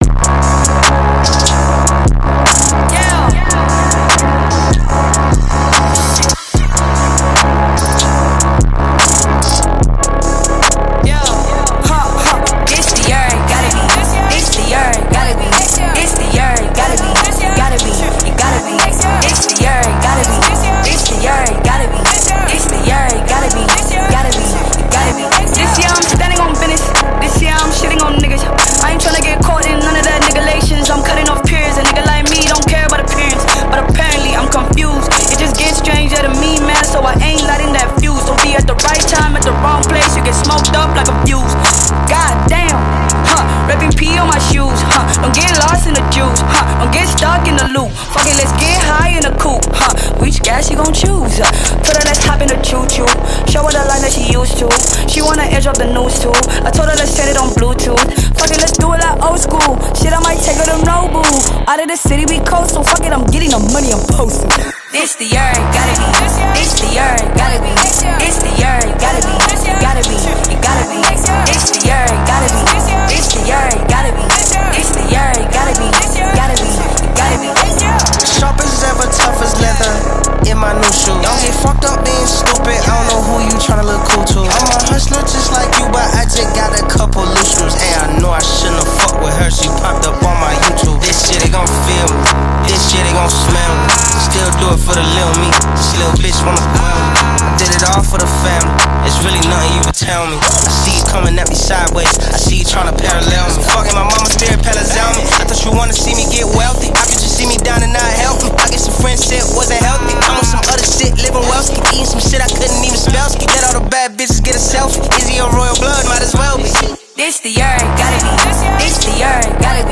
Talented South African rapper and singer
is melodious and perfect for enjoying during the summer